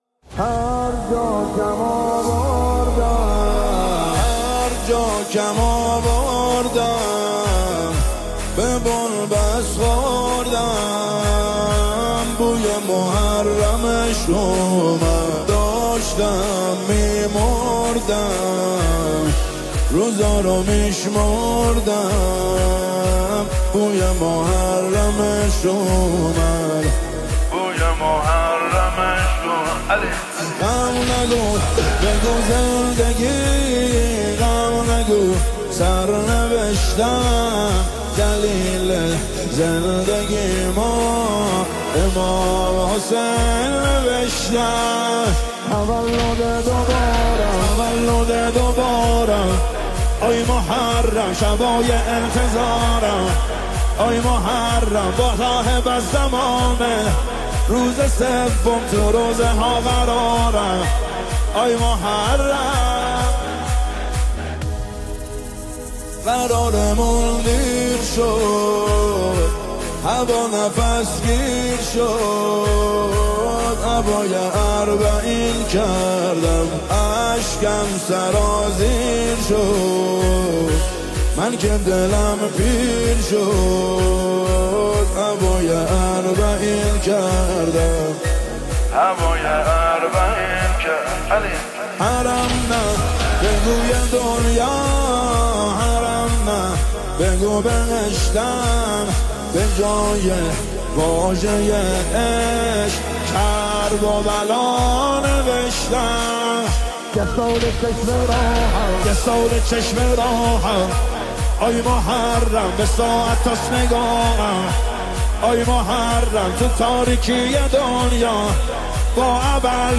دانلود مداحی دلنشین